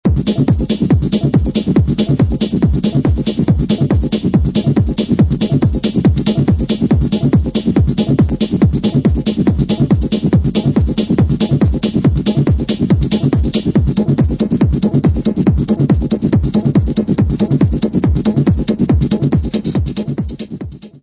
: stereo; 12 cm
Music Category/Genre:  Pop / Rock